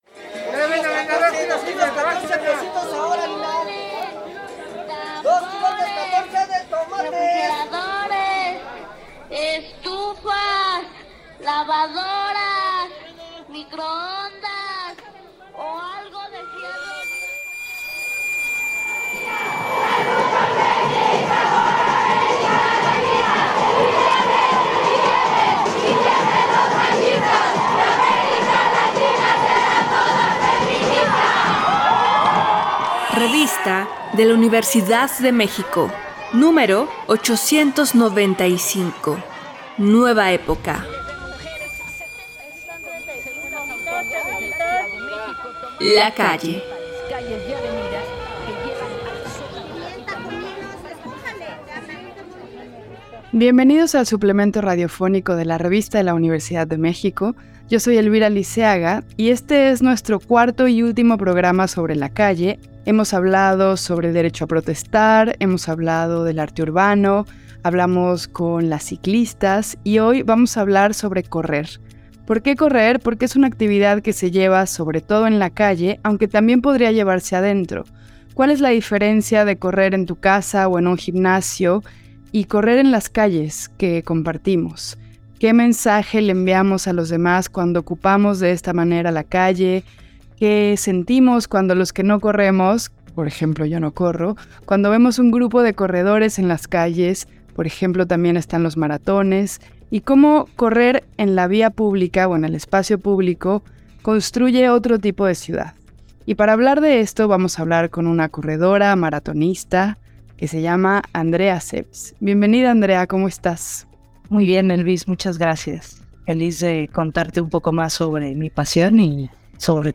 Fue transmitido el jueves 27 de abril de 2023 por el 96.1 FM.